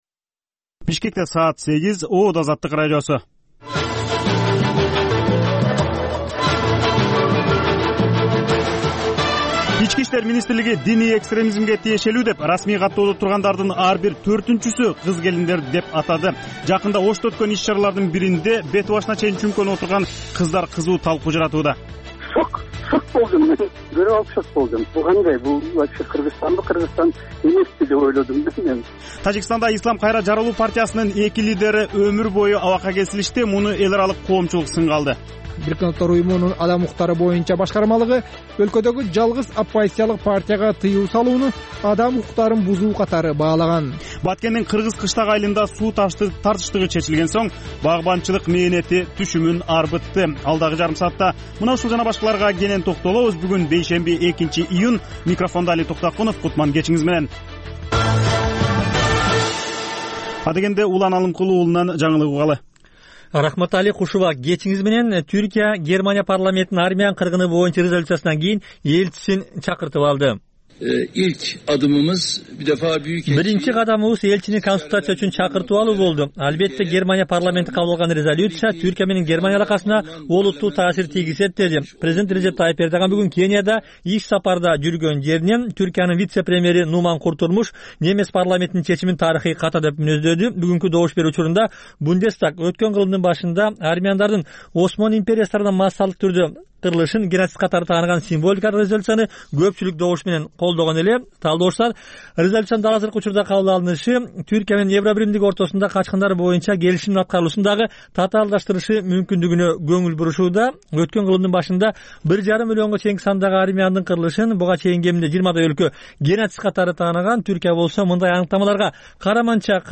"Азаттык үналгысынын" бул кечки бир сааттык берүүсү ар күнү Бишкек убакыты боюнча саат 20:00дан 21:00гө чейин обого түз чыгат.